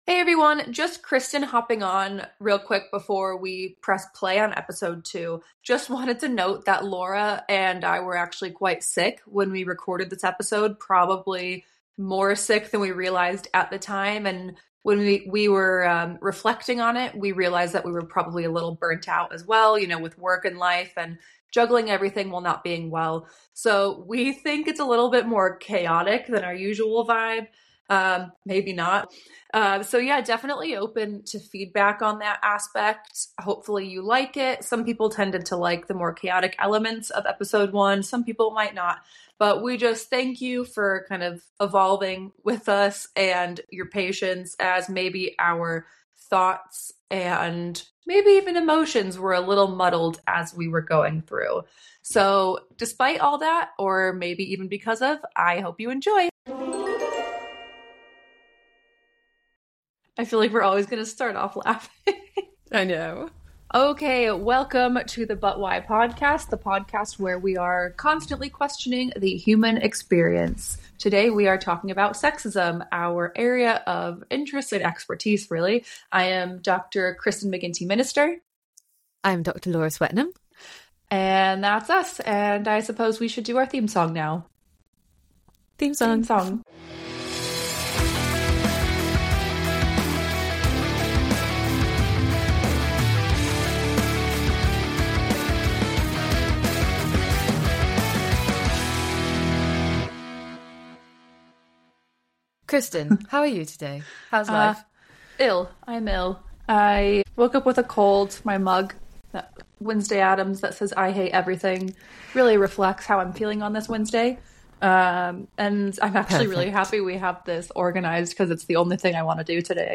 In this episode of But Why: Real talk on messy minds and messier systems, psychologists